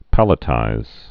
(pălĭ-tīz)